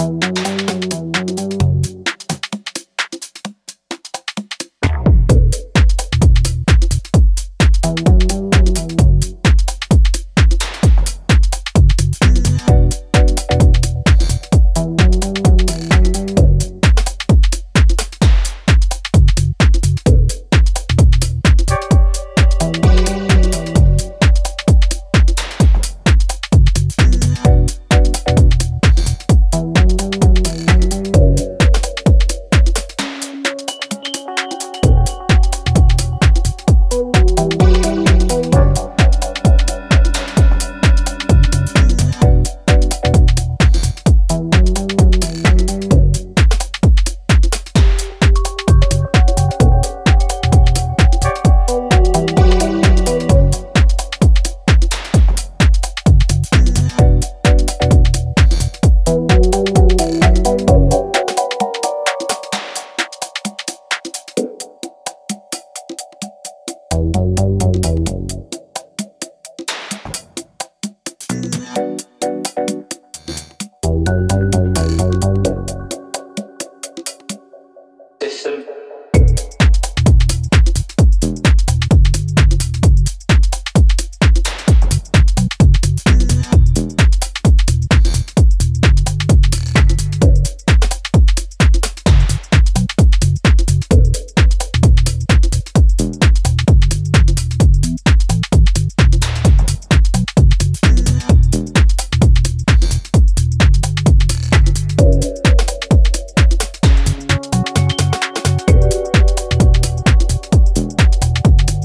drawing on minimal elements and classic vocal hooks